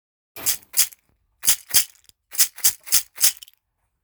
ストローがらがら ラウンドハンドル ベル入
●シェイカー・小物・笛
水草を編み上げたカラフルなカゴの中に、真鍮ベルが入っています。振ると優しいベルの音で心が和みます。
素材： 水草 真鍮ベル